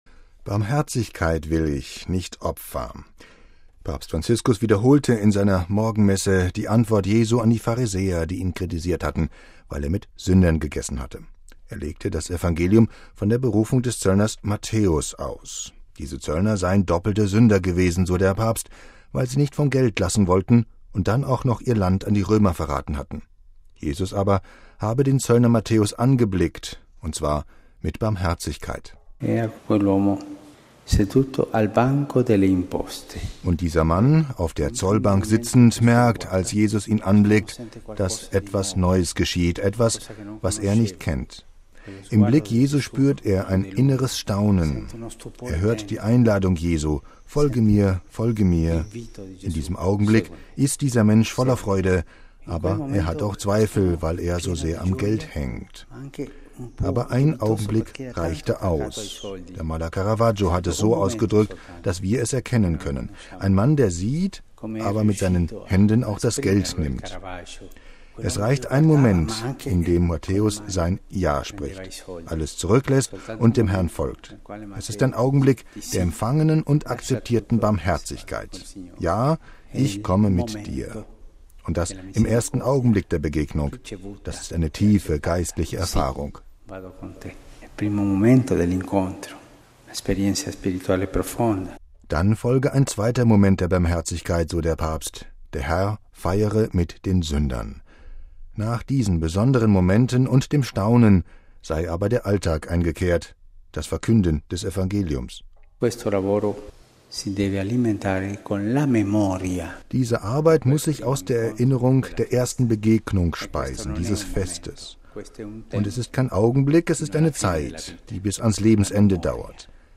Papstpredigt: Angeschaut von Jesu Barmherzigkeit
MP3 „Barmherzigkeit will ich, nicht Opfer“: Papst Franziskus wiederholte in seiner Morgenmesse die Antwort Jesu an die Pharisäer, die ihn kritisiert hatten, weil er mit Sündern gegessen hatte. Er legte das Evangelium von der Berufung des Zöllners Matthäus aus.